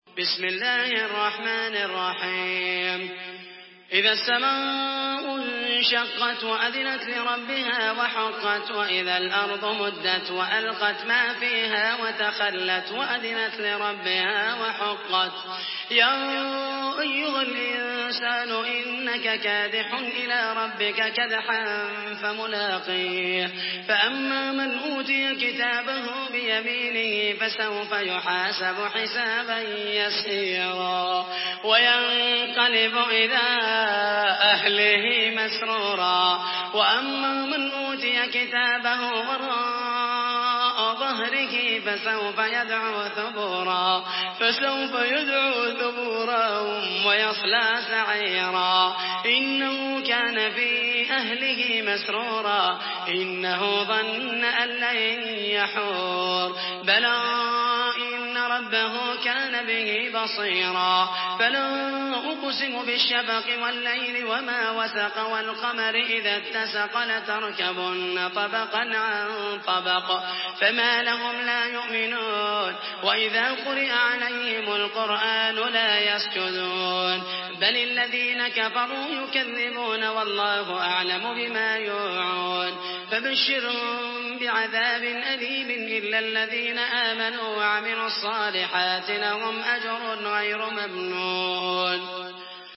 Surah আল-ইনশিক্বাক MP3 by Muhammed al Mohaisany in Hafs An Asim narration.
Murattal Hafs An Asim